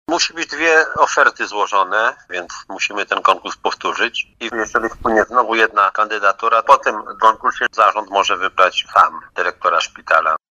Mówi starosta satalowowolski Janusz Zarzeczny.